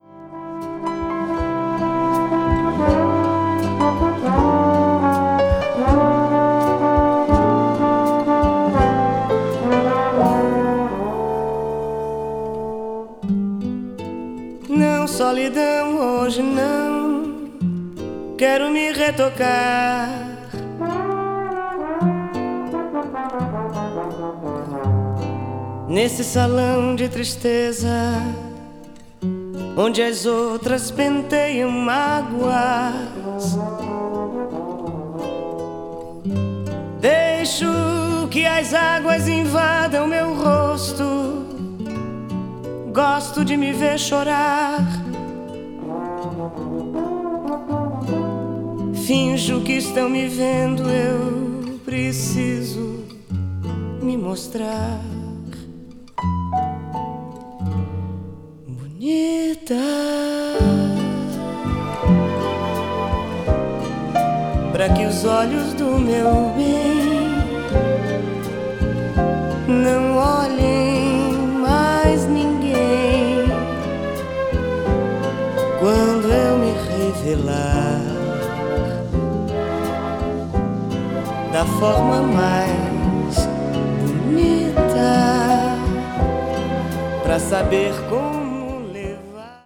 brazil   choro   folk   mpb   samba   world music